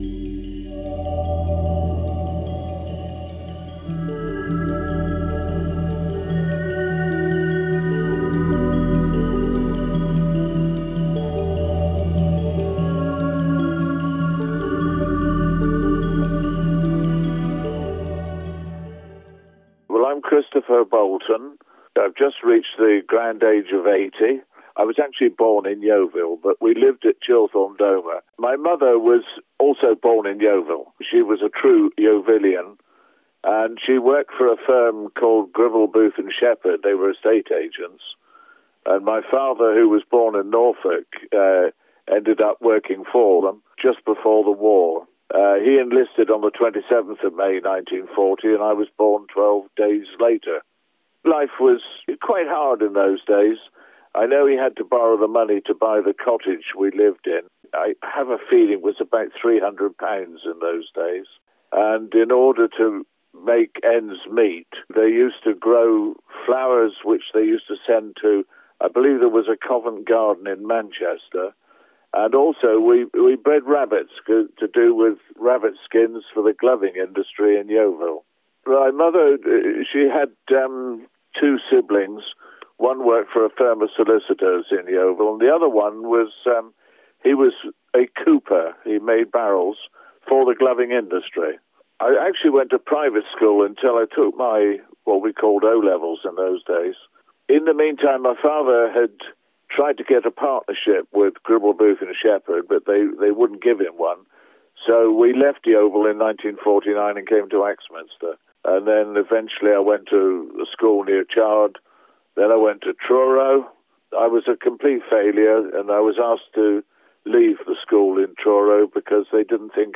interviewed residents and staff from Somerset Care and local elders from Yeovil